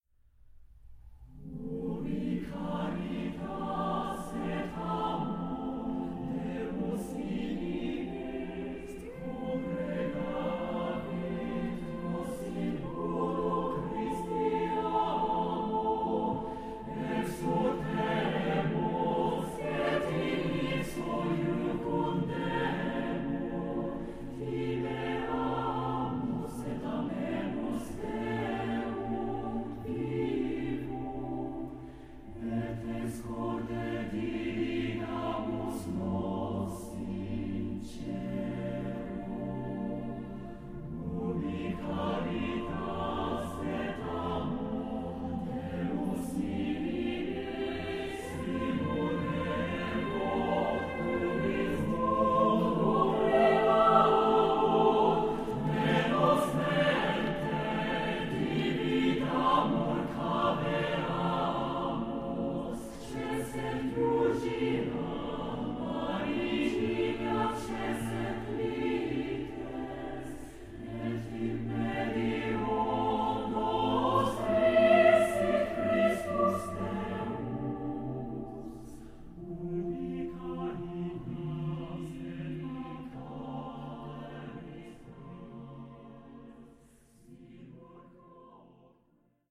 SATB (4 voix mixtes) ; Partition complète.
Hymne (sacré). Motet.
Consultable sous : 20ème Sacré Acappella